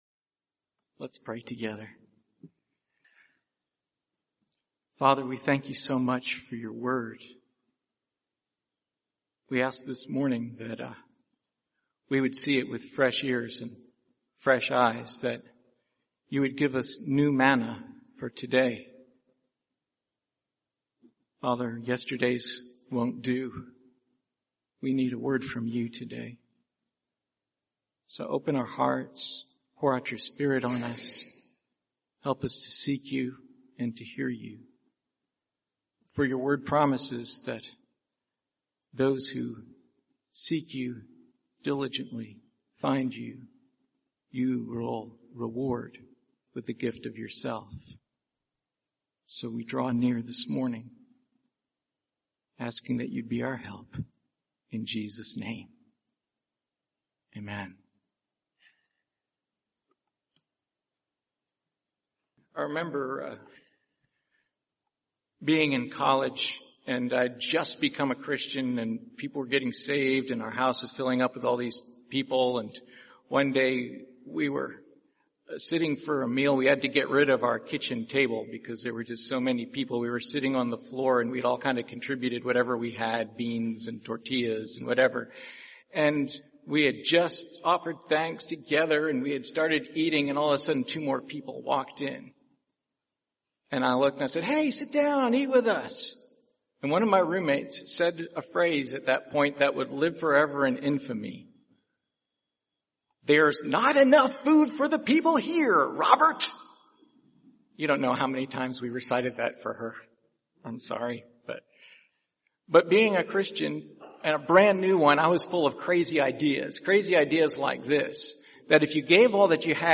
Passage: 1 Corinthians 11:17-37 Service Type: Sunday Morning